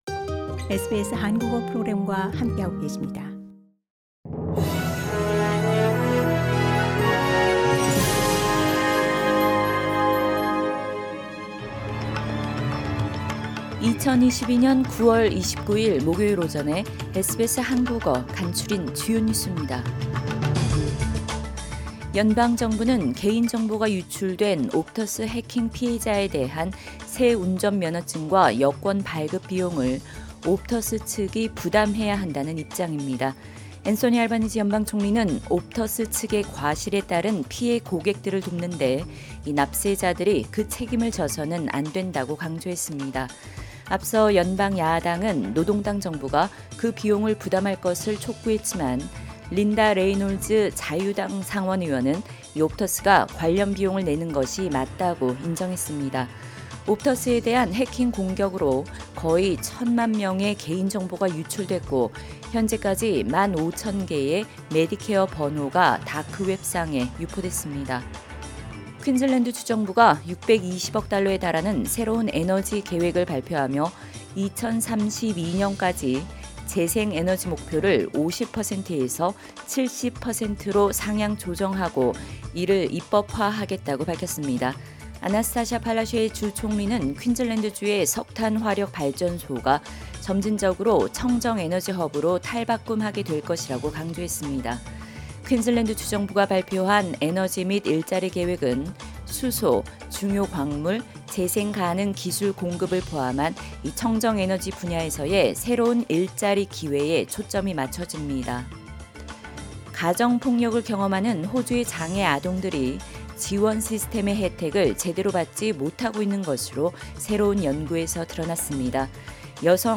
2022년 9월 29일 목요일 아침 SBS 한국어 간추린 주요 뉴스입니다.